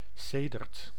Ääntäminen
IPA: [sə]